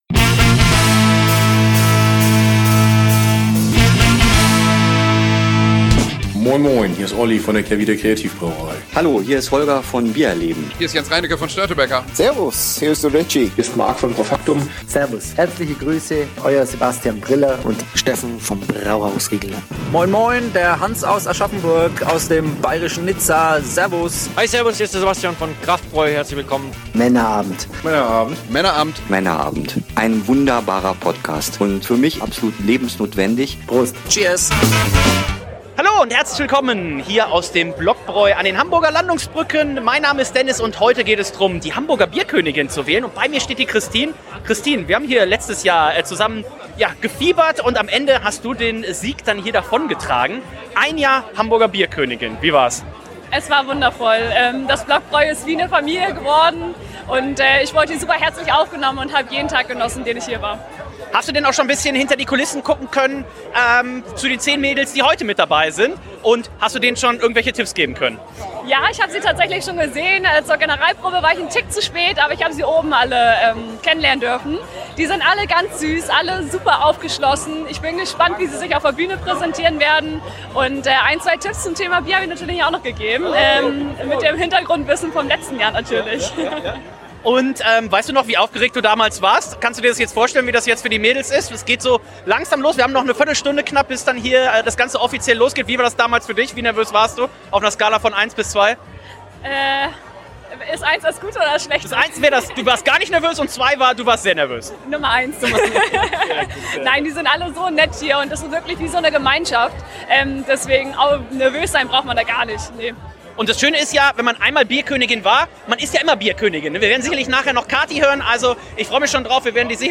🍻👑 Ein Abend voller Spannung, aufregender Gespräche und natürlich fantastischer Biere – mitten in einer der schönsten Locations Hamburgs.